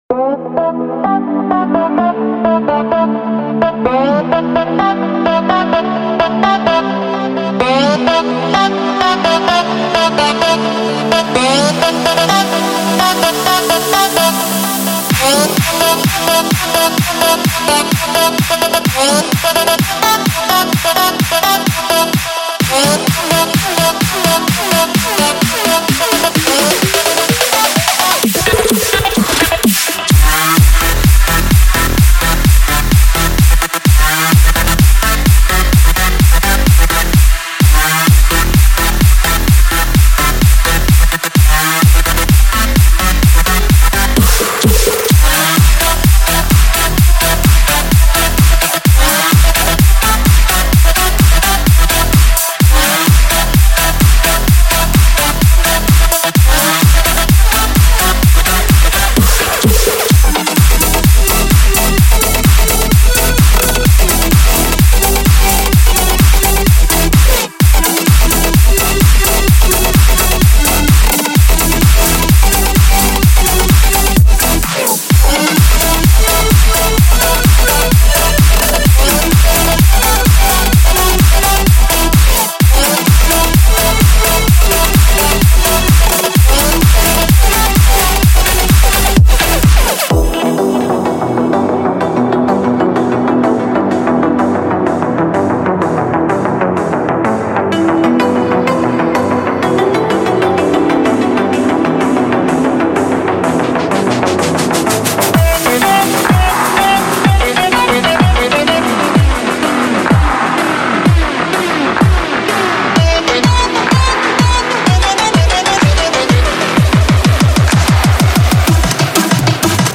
3. EDM
融合了K-Pop和Melbourne Bounce的元素，这款令人耳目一新的套装包含5个制作套件，声音直接来自首尔。